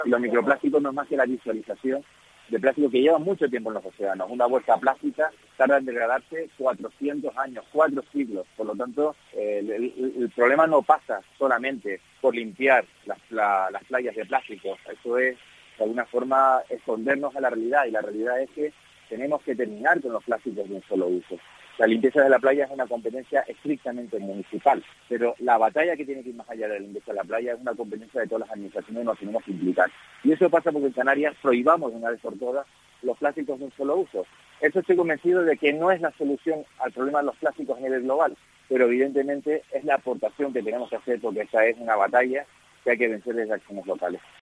José Antonio Valbuena, consejero de Medio Ambiente del Cabildo
El consejero de Medio Ambiente del Cabildo de Tenerife, José Antonio Valbuena, ha mostrado en los micrófonos de Mediodía COPE su preocupación por la continua aparición de microplásticos en distintas zonas del litoral de la isla, como La Punta de Abona, en Arico.